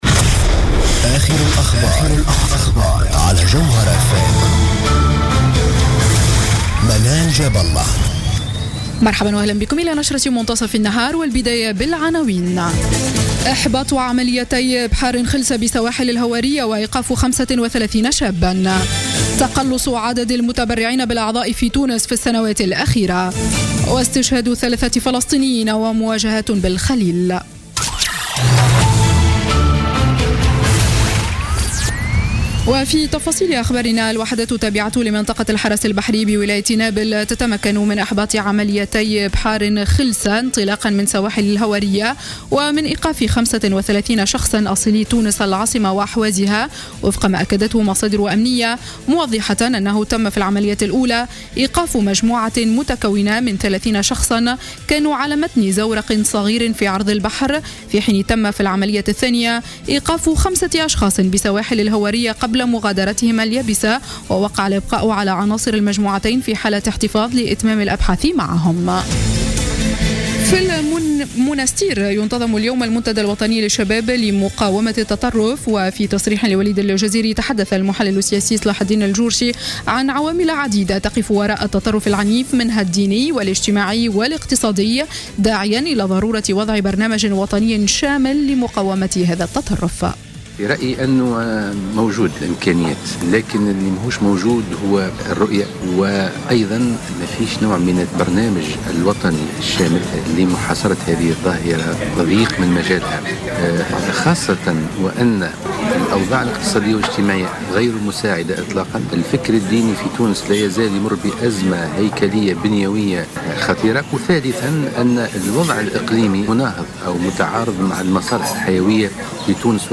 نشرة أخبار منتصف نهار يوم السبت 17 أكتوبر 2015